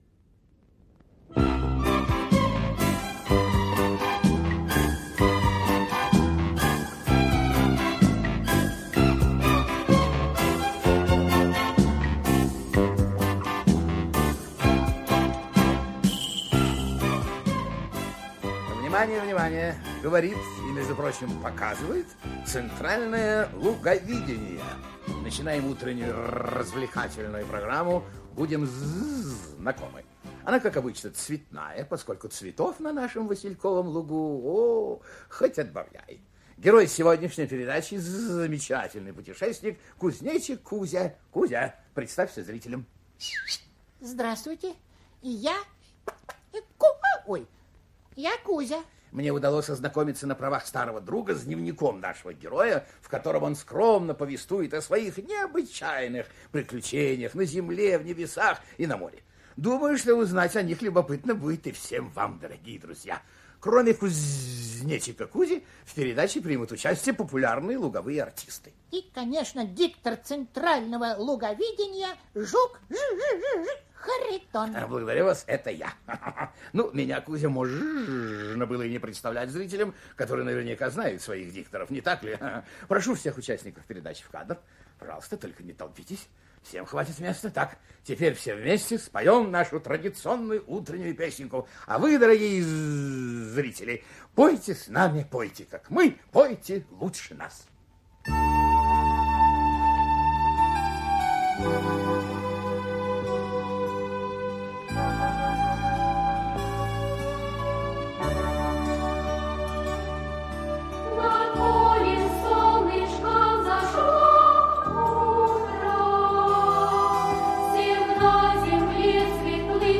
Слушайте Приключения кузнечика Кузи - аудиосказка Пляцковского М.С. Кузнечик Кузя в стране Жарафрике спасает ее обитателей от очковой Змеи.